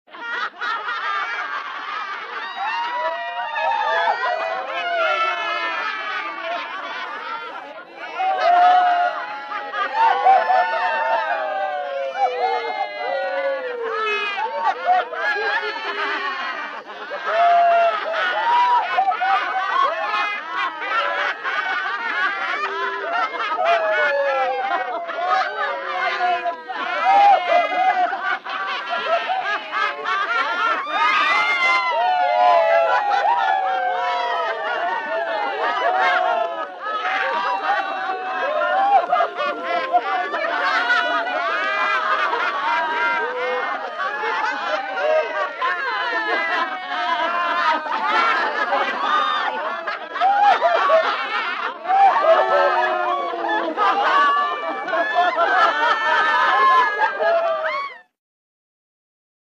Звуки насмешки
Толпа издевается над неудачей человека